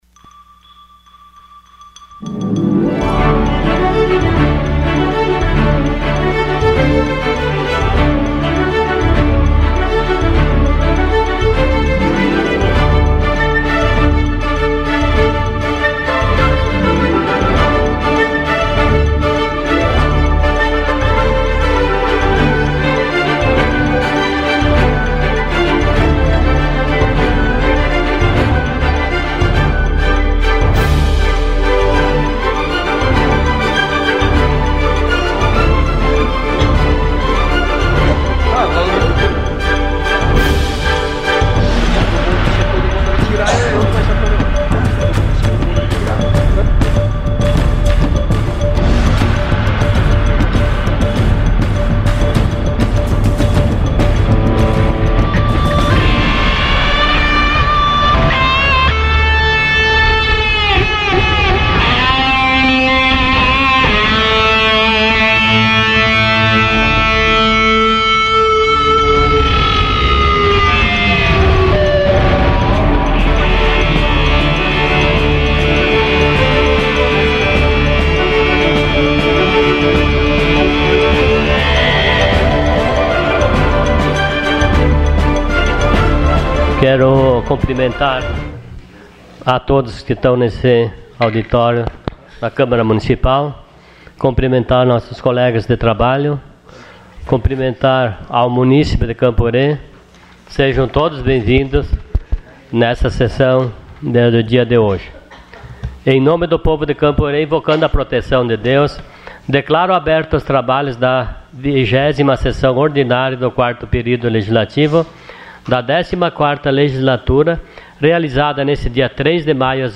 Sessão Ordinária dia 03 de maio de 2016.